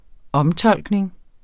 Udtale [ ˈʌmˌtʌlˀgneŋ ]